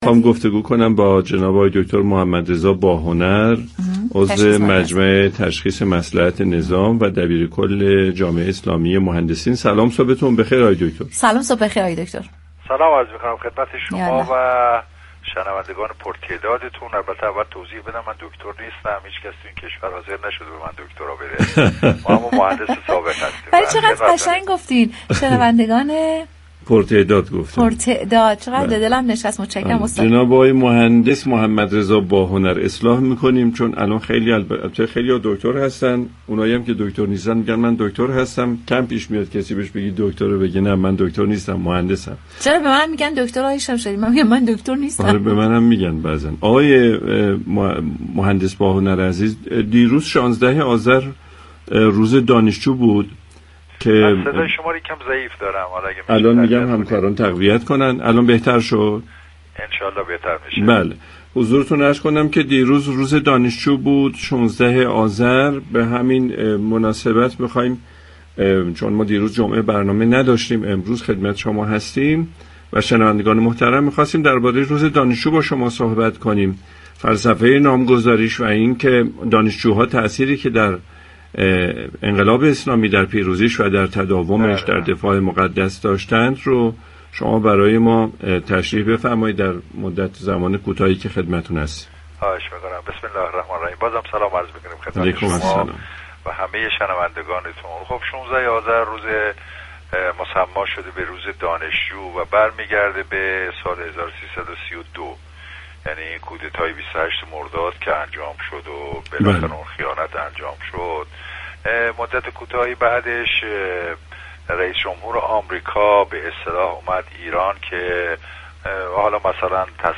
متاسفانه امروز اساتید دانشگاه برای ارتقاء رده خودشان كلاس‌های آزاد اندیشی برگزار می‌كنند به گزارش پایگاه اطلاع رسانی رادیو تهران، محمدرضا باهنر عضو حقیقی مجمع تشخیص مصلحت نظام در گفت و گو با «بام تهران» اظهار داشت: قرار بود در دانشگاه‌ها كرسی‌های آزاد اندیشی برگزار شود ولی متاسفانه امروز اساتید دانشگاه برای ارتقاء رده خودشان كلاس‌های آزاد اندیشی برگزار می‌كنند؛ مقصود ما كرسی آزاد اندیشی بود تا دانشجویان با شركت در این كرسی‌ها بحث و مذاكره كنند.